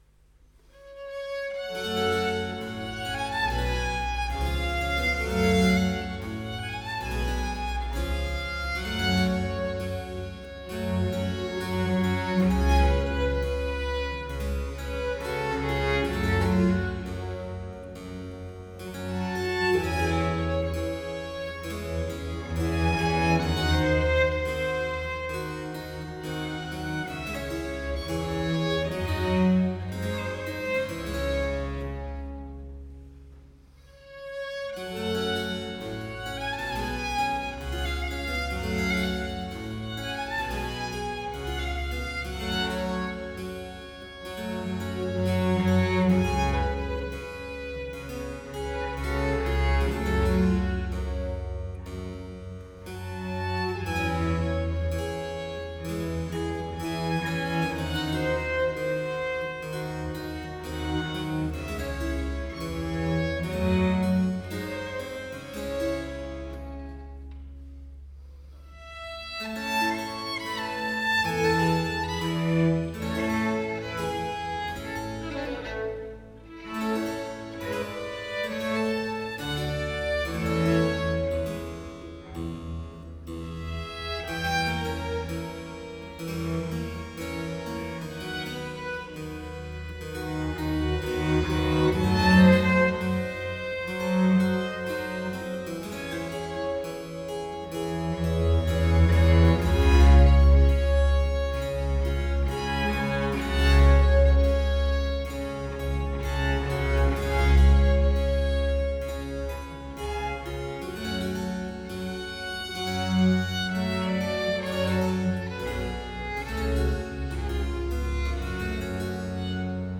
Live recordering 07 ottobre 2017